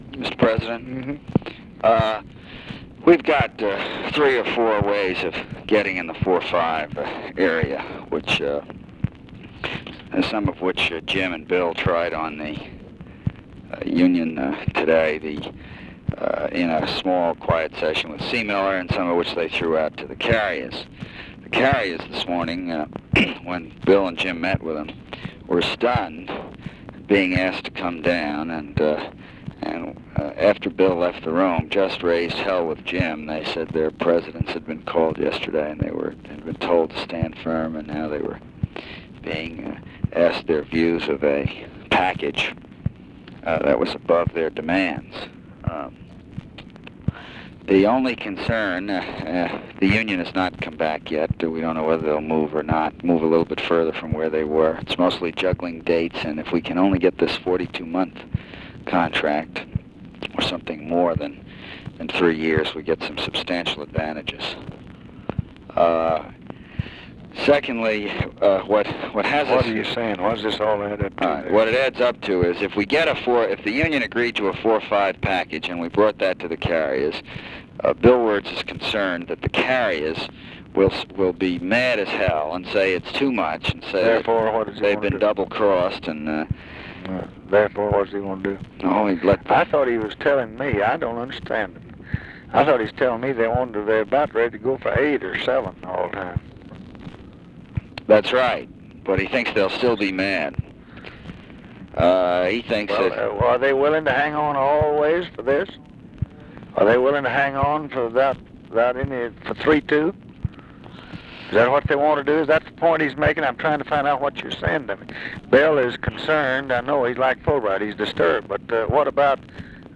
Conversation with JOSEPH CALIFANO, July 28, 1966
Secret White House Tapes